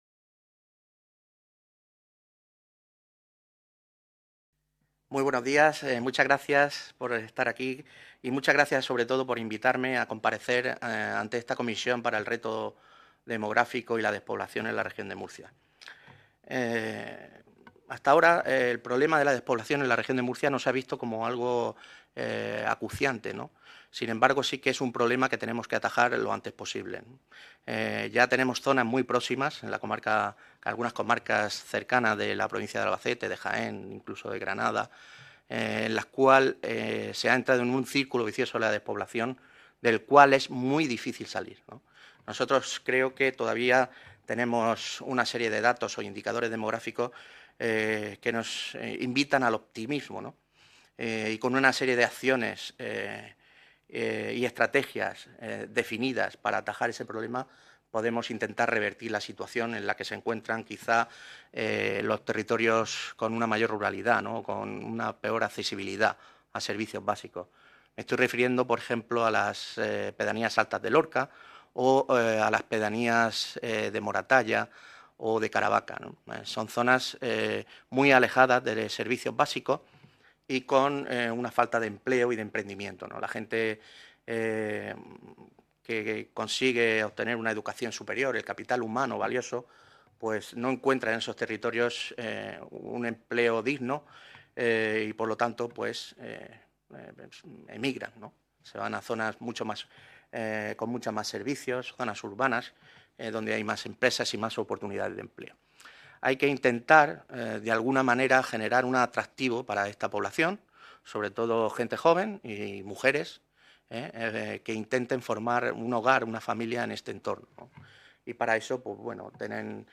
Ruedas de prensa tras la Comisión Especial de Estudio para abordar el Reto Demográfico y la Despoblación en la Región de Murcia